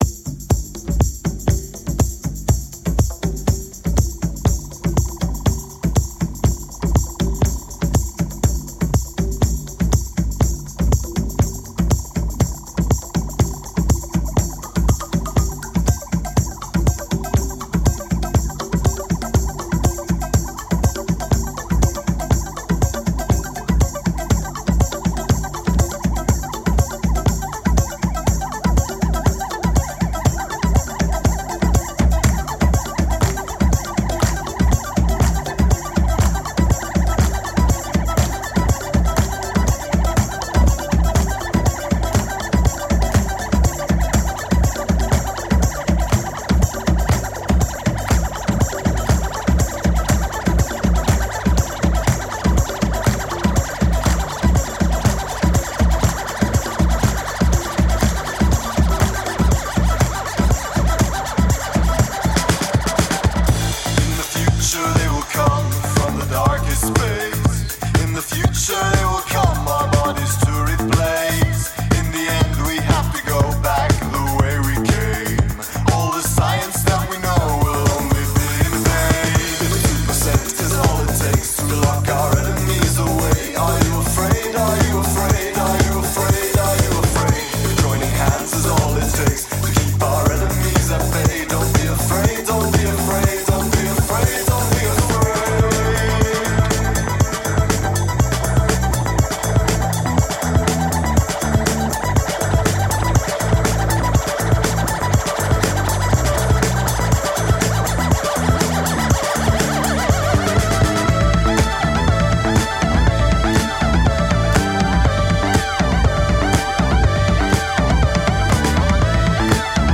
electropop funk and disco outfit